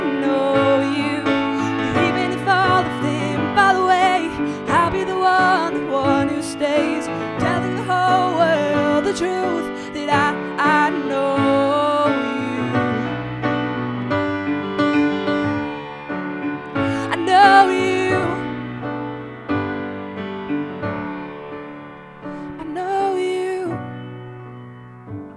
• Piano/Vocal Listening Demo
*Recommended for piano/vocal or full praise team